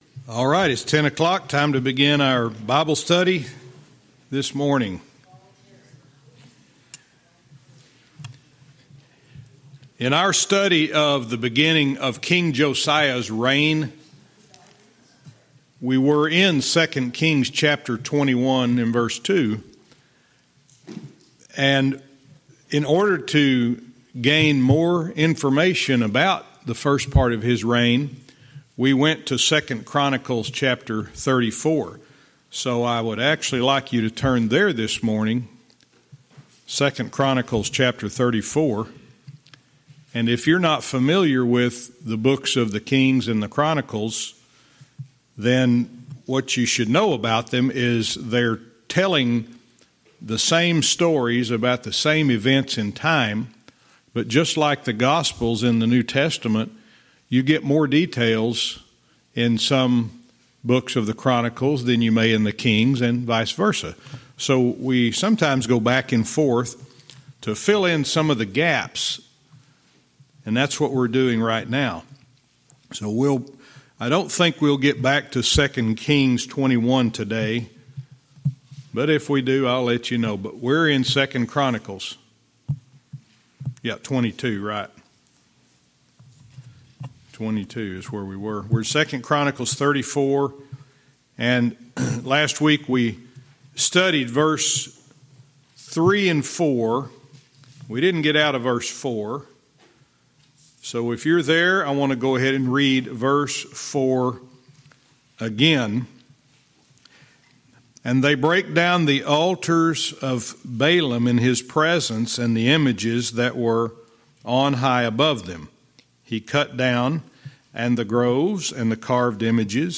Verse by verse teaching - 2 Kings 22:2 & 2 Chronicles 34:4-6